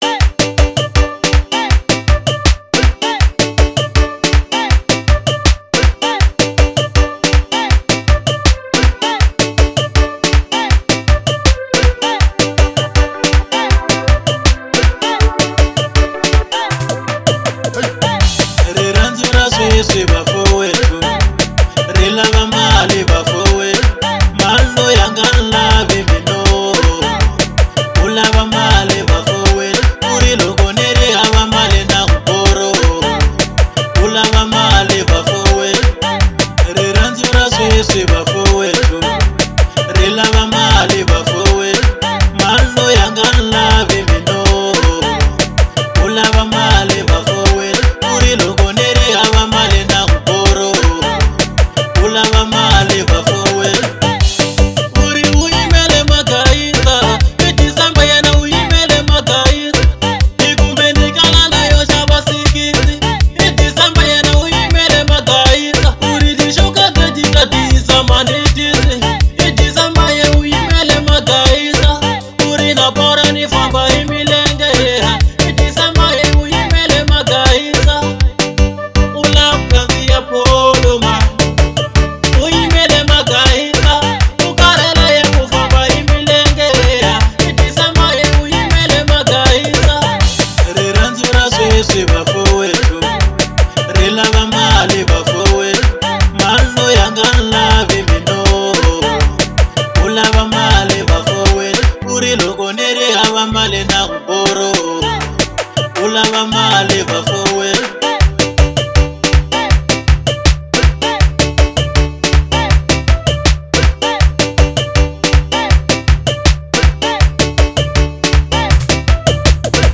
04:50 Genre : Xitsonga Size